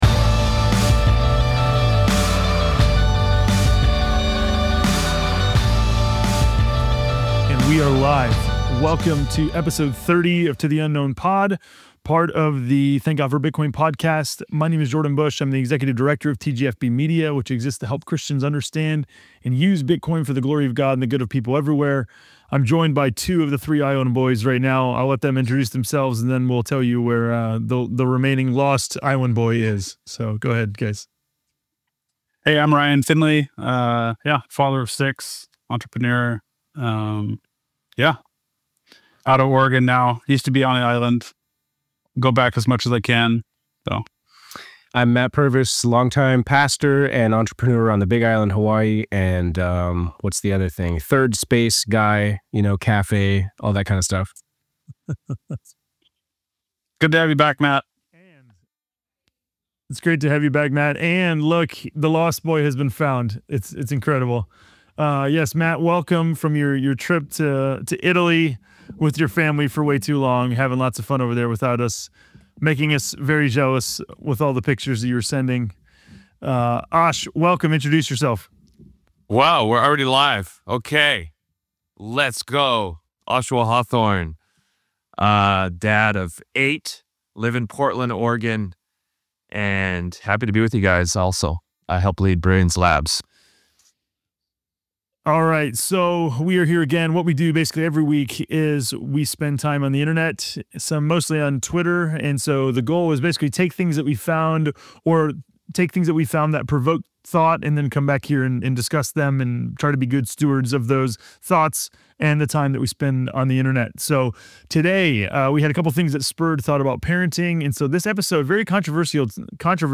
The guys discuss the alarming trend of youth disengagement from faith, particularly within the context of modern American parenting. They explore the statistics surrounding youth ministry effectiveness, the role of parents in faith formation, and the cultural influences that contribute to this issue. The conversation emphasizes the importance of genuine faith, family dynamics, and the need for intentional parenting.